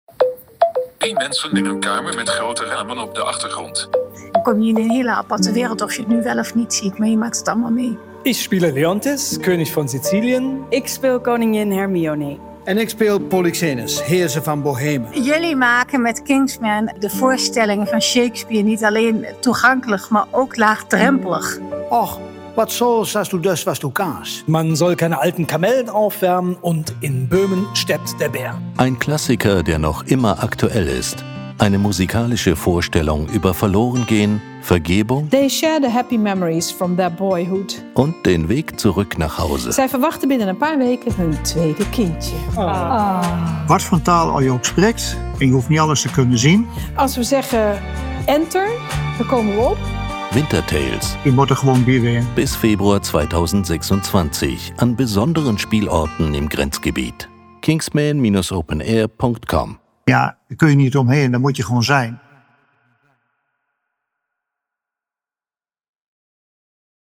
Winter Tales wird auf beiden Seiten der Grenze im bewährten Sprachmix aus Deutsch, Niederländisch und Platt mit einer Prise Englisch gespielt – leicht zugänglich und voller Energie.
D-Winter-Tales-Audiotrailer.mp3